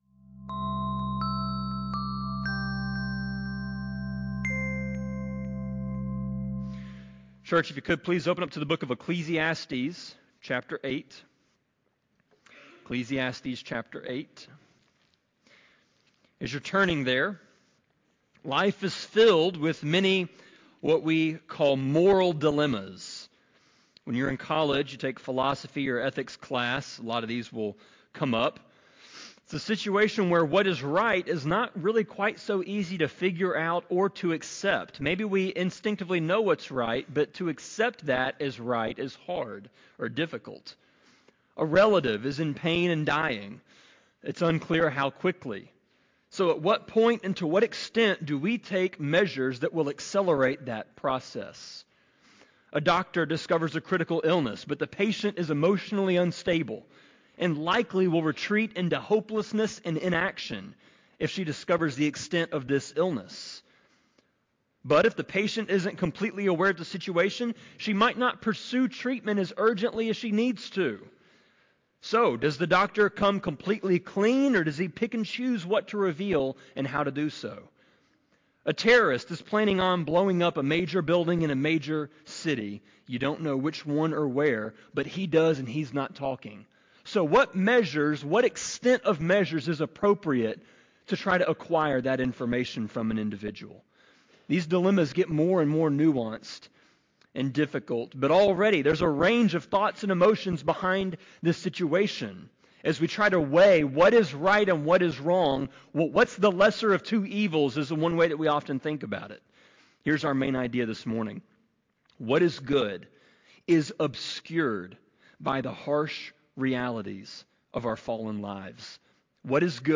Sermon-25.4.6-CD.mp3